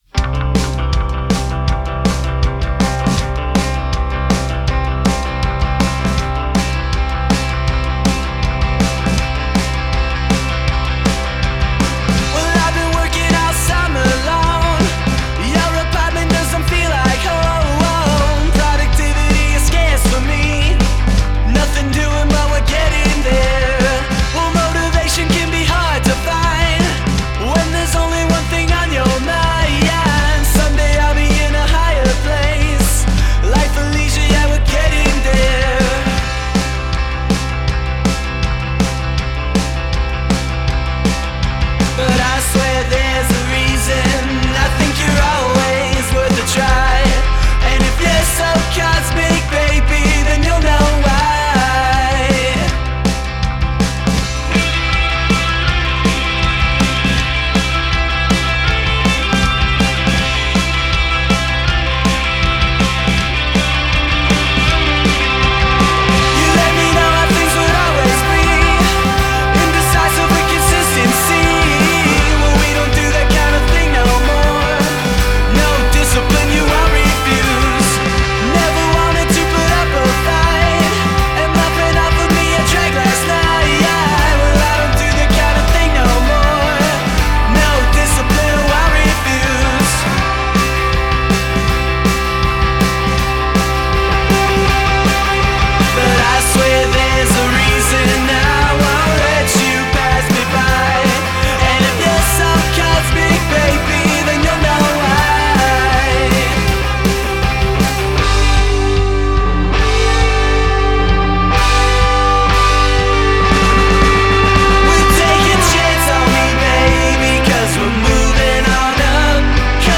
Fun, retro sounding vintage pop-rock.